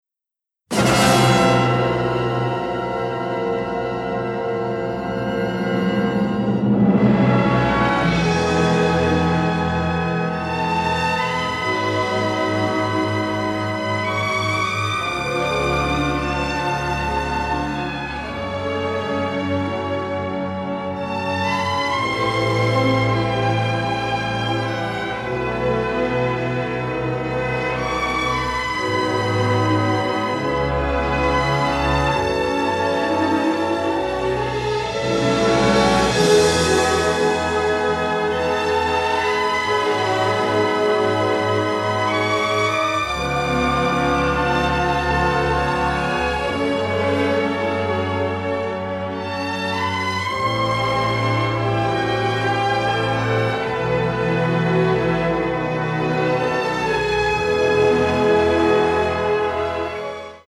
great exotica and pop tunes